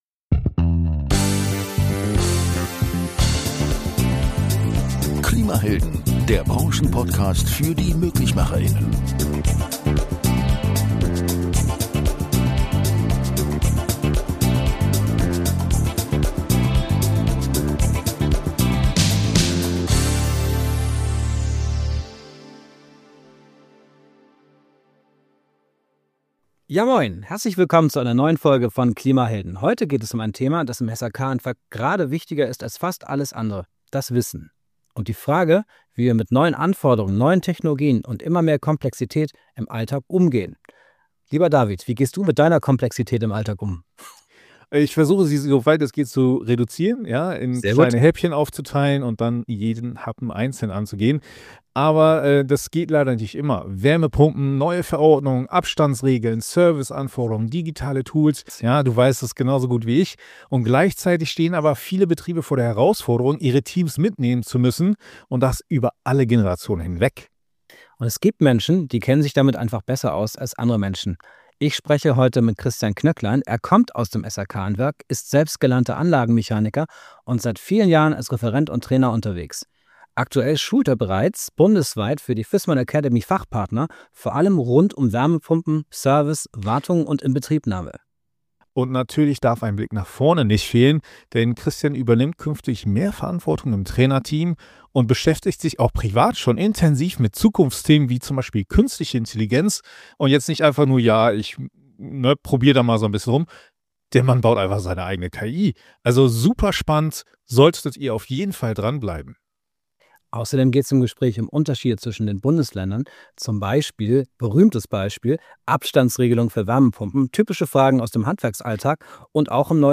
Ein Gespräch über Lernen, Offenheit und die Realität im Handwerk.